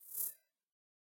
camera_focus.ogg